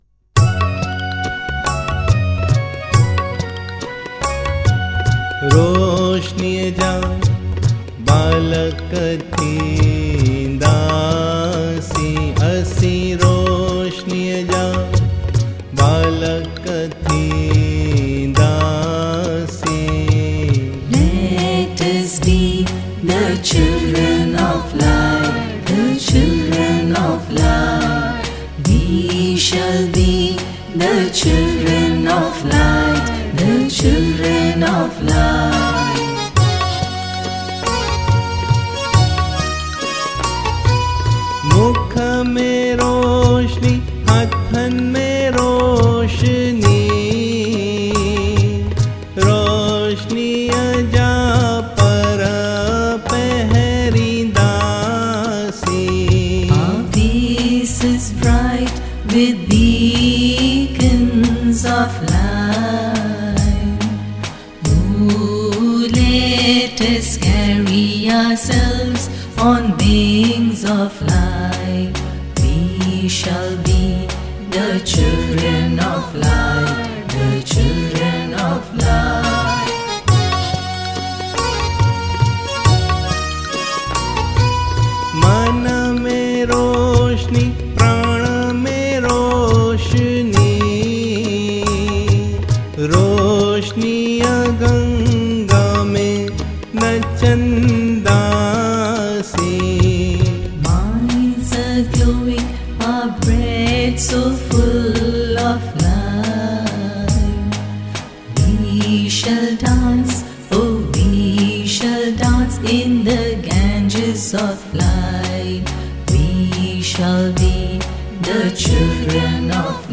Hymns
Recorded at: Line-In Studio